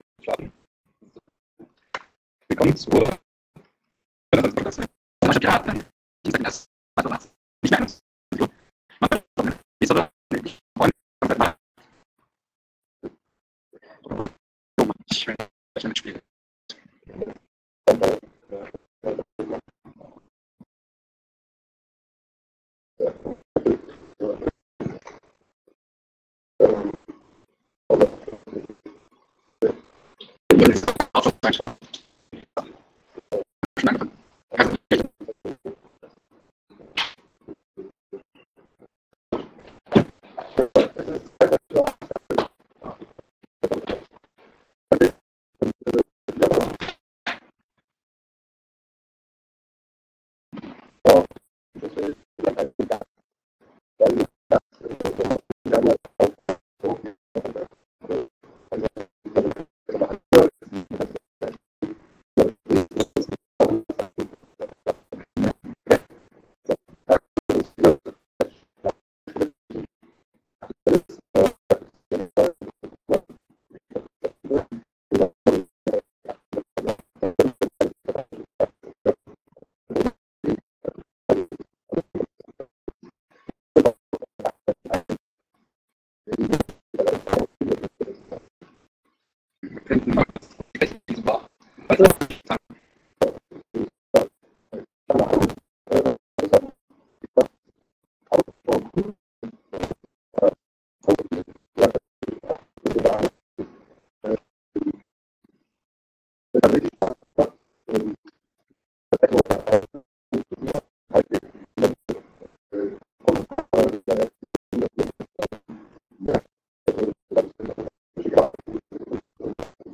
Mumble: NRW-Server, Gliederungen, Sachsen, temporäre Räume, Neustadtpiraten